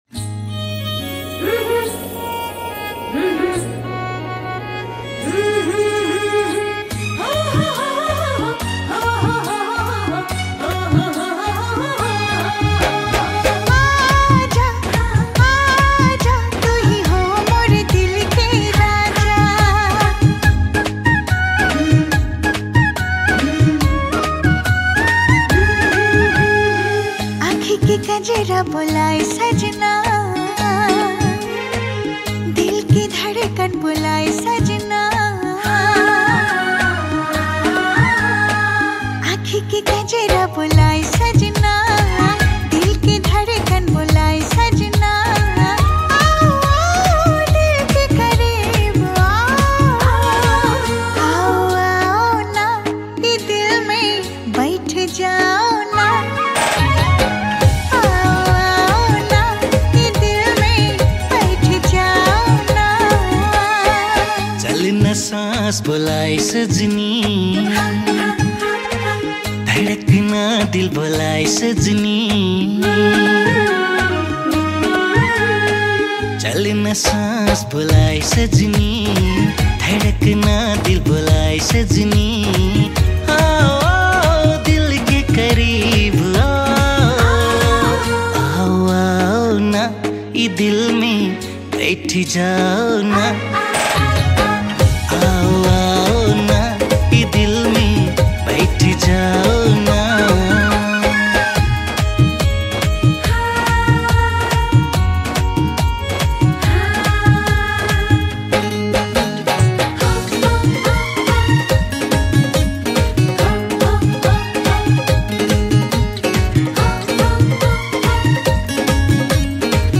New Tharu Mp3 Song